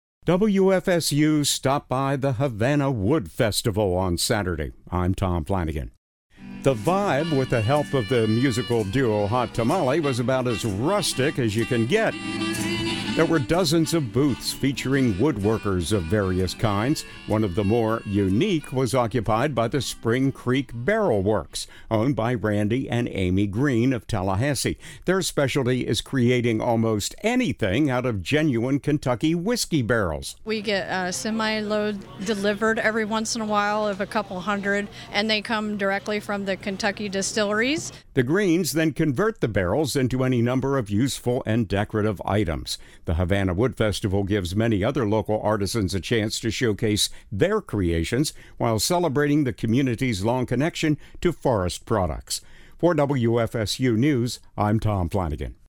The annual Havana Wood Festival took place on Saturday, Sept. 6.
The event’s vibe, with the help of the musical duo Hot Tamale, was about as rustic as you can get.